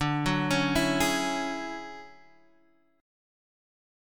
DM#11 chord